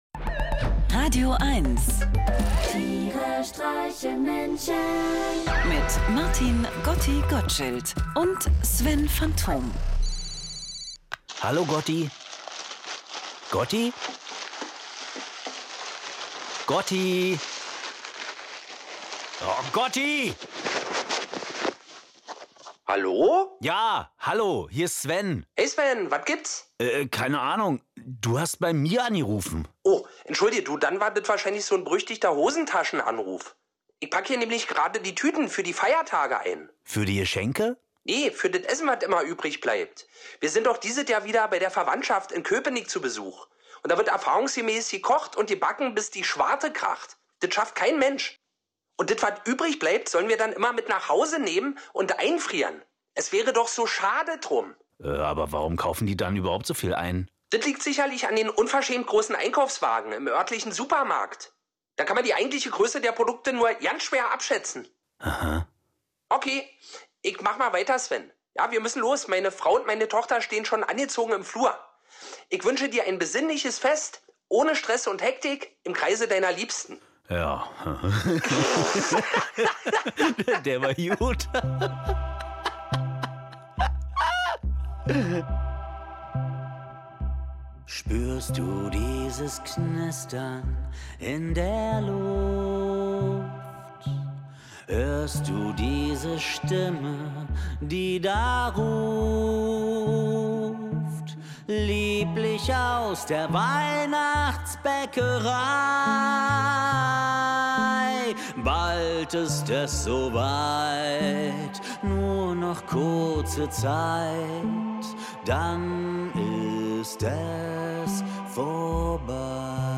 Einer liest, einer singt und dabei entstehen absurde, urkomische, aber auch melancholische Momente. Irgendwie mitten aus dem Leben und irgendwie bekloppt.